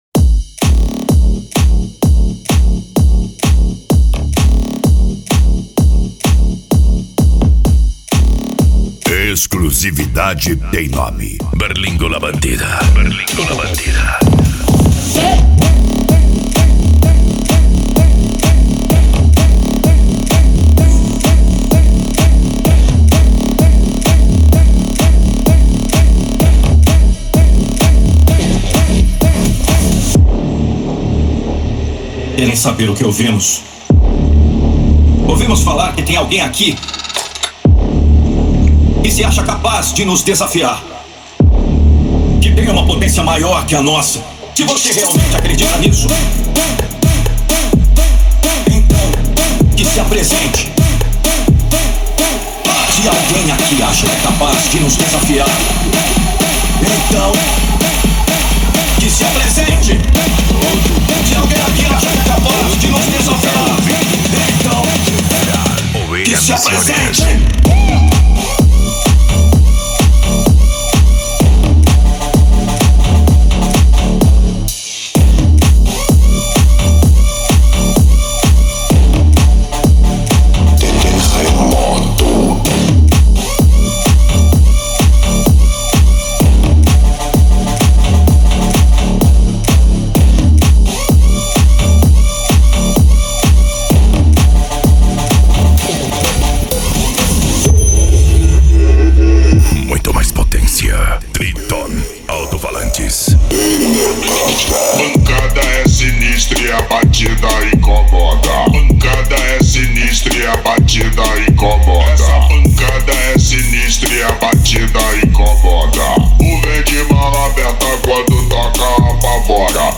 Remix
PANCADÃO